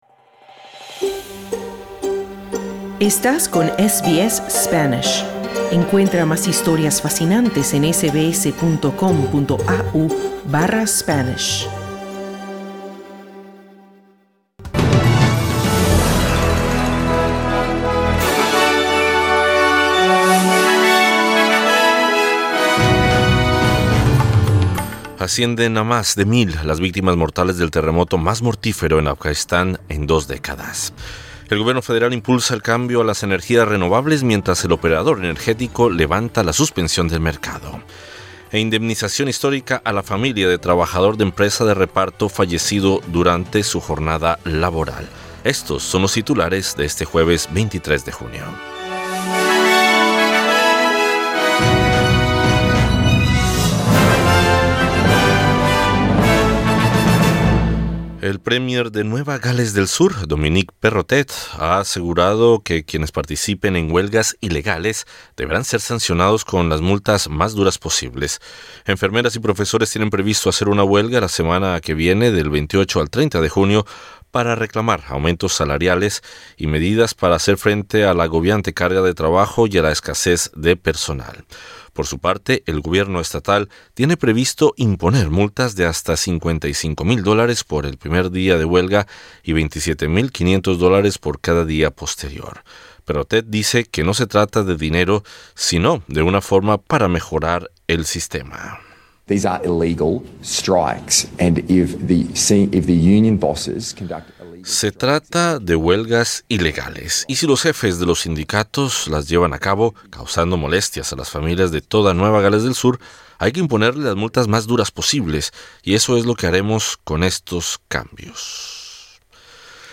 Noticias SBS Spanish | 23 junio 2022